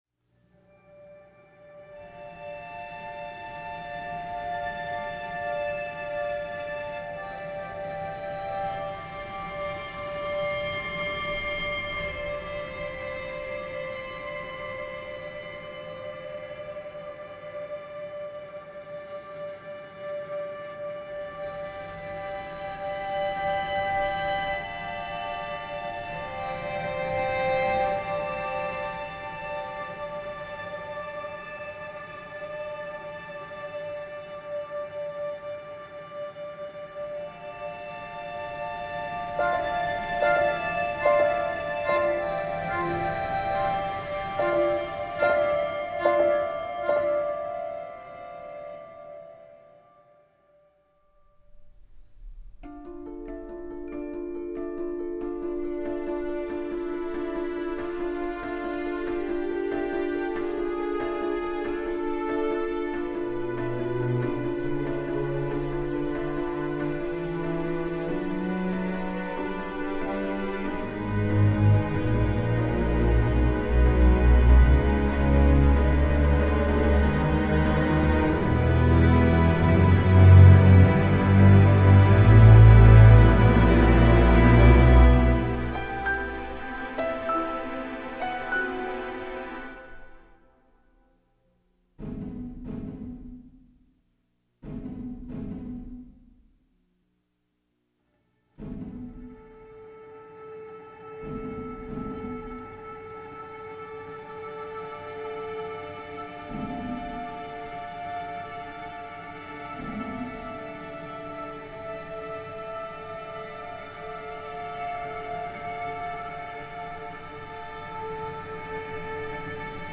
Genre : Soundtrack